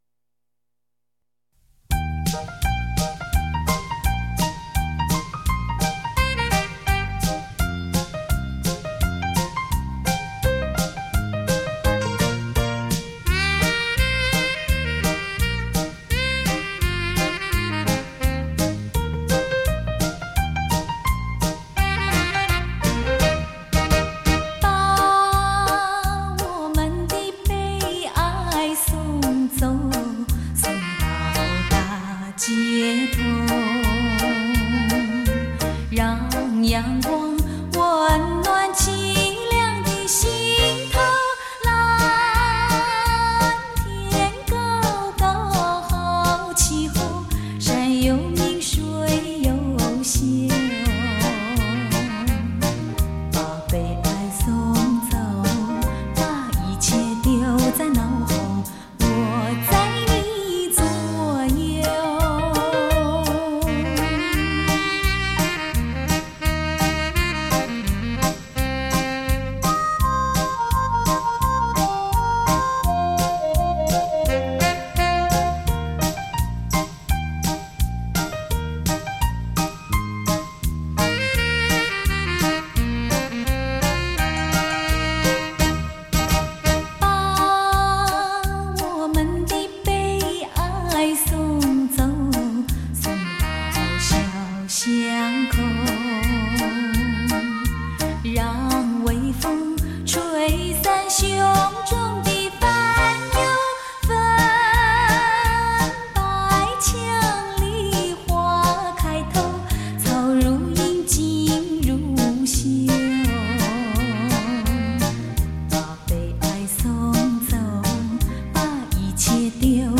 幽雅的乐曲 甜润的歌声 回荡在你我心间
吉鲁巴